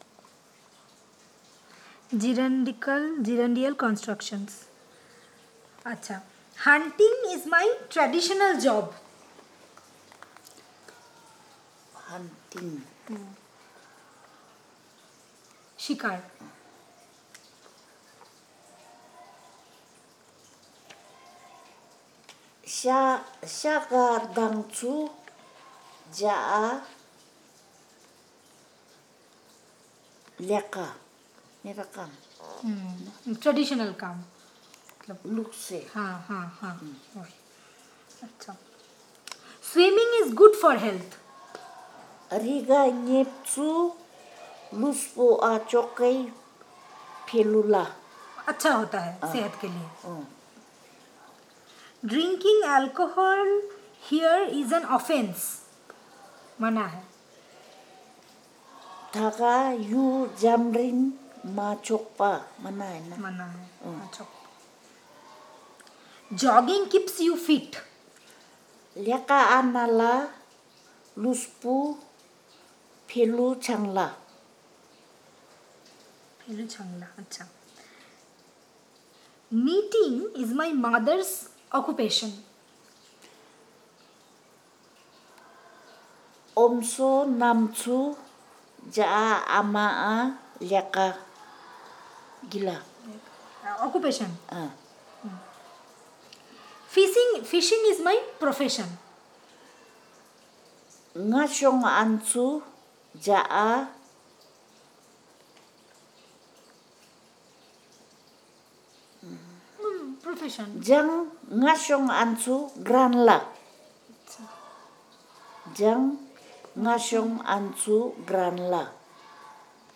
NotesThis is an elicitation of sentences about gerundial construction using the questionnaire.